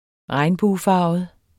Udtale [ -ˌfɑːvəð ]